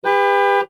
horn.ogg